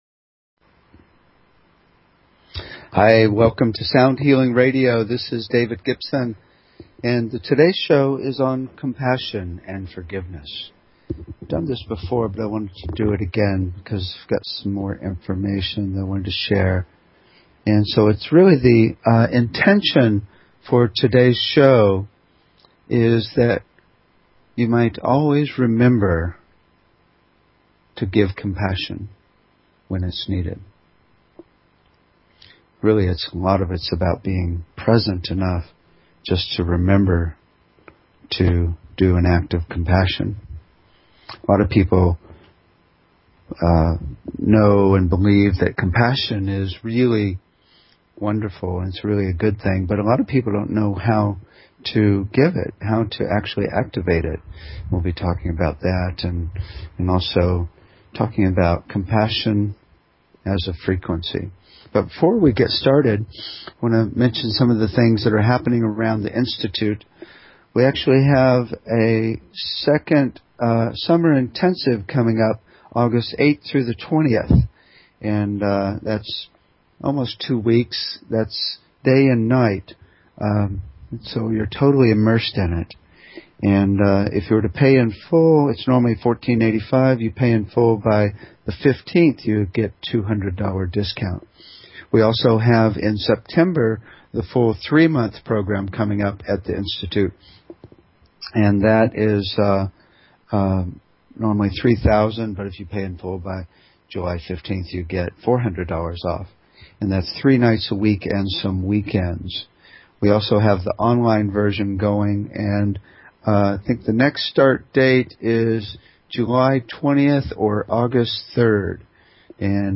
Talk Show Episode, Audio Podcast, Sound_Healing and Courtesy of BBS Radio on , show guests , about , categorized as
What it really is and various techniques for giving Compassion (to others and to yourself). We'll listen to recordings based on the intention of Compassion and do guided meditations to find the sound of Compassion for yourself.
The show is a sound combination of discussion and experience including the following topics: Toning, Chanting and Overtone Singing - Root Frequency Entrainment - Sound to Improve Learning -Disabilities - Using Sound to Connect to Spirit - Tuning Fork Treatments - Voice Analysis Technologies - Chakra Balancing - Sound to Induce Desired S